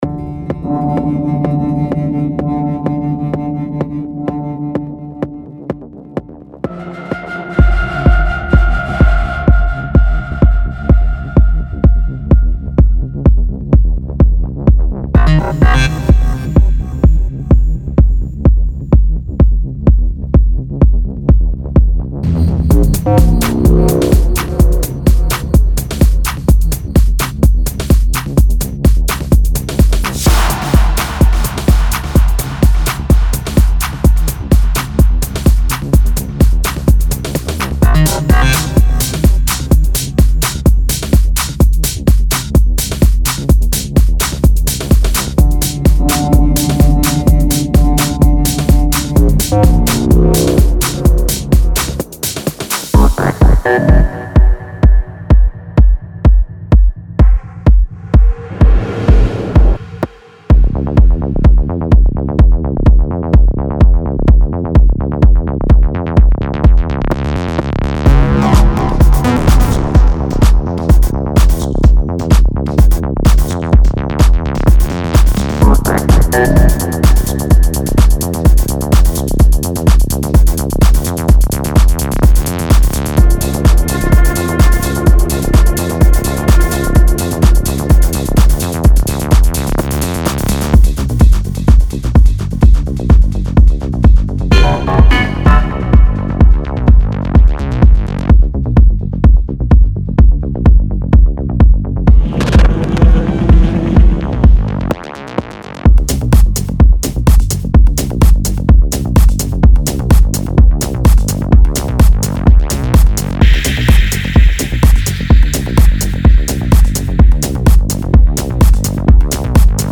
Tech House Techno
127 BPM